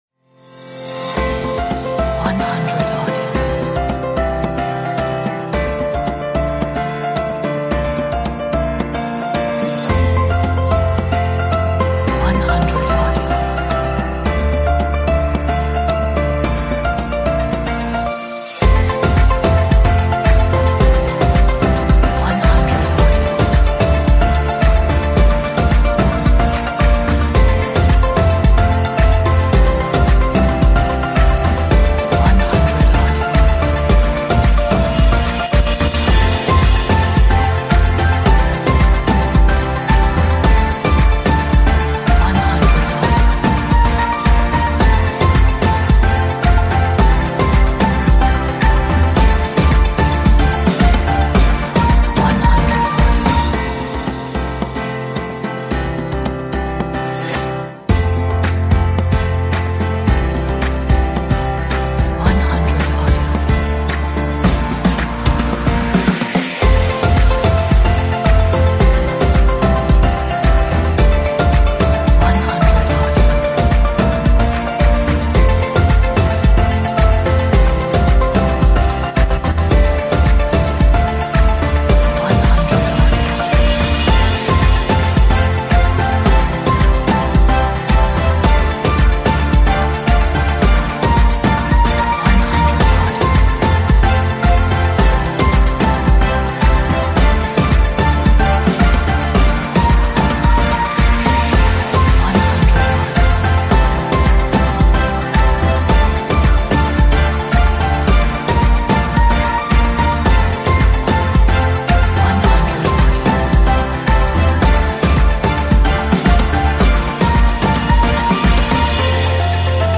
is a upbeat, positive, motivational, inspirational,